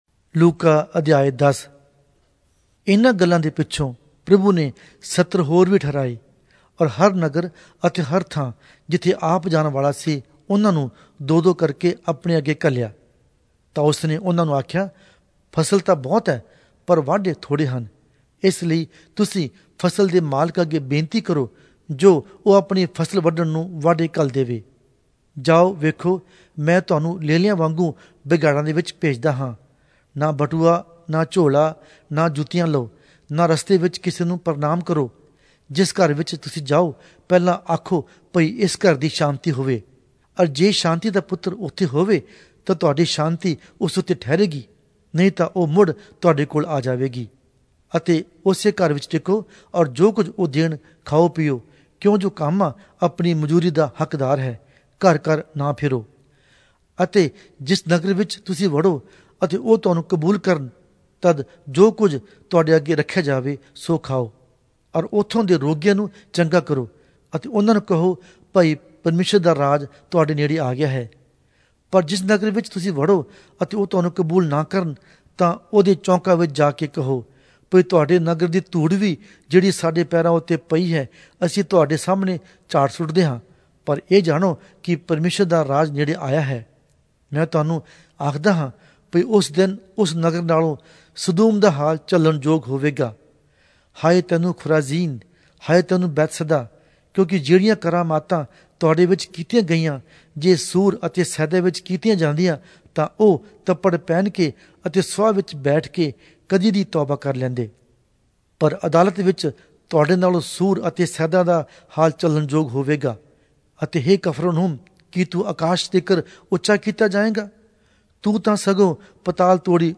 Punjabi Audio Bible - Luke All in Irvhi bible version